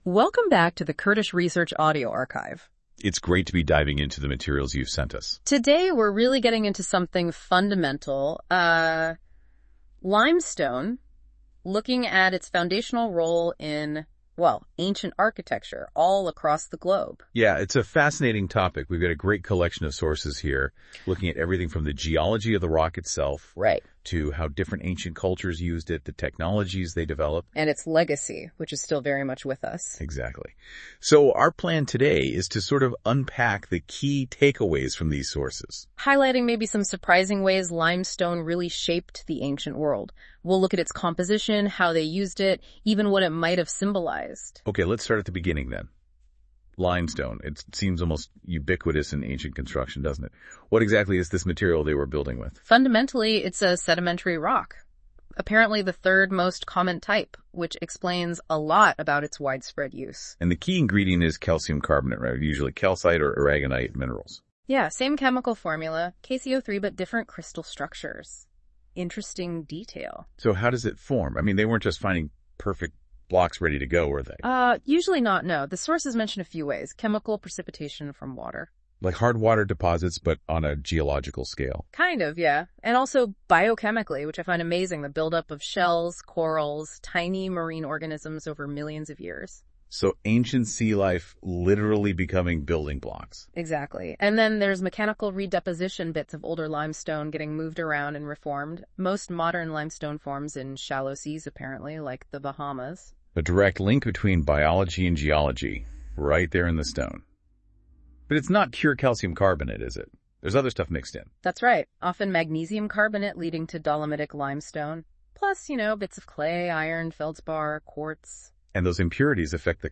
Note: This was made with AI research and AI audio output, and does not conform to academic standards.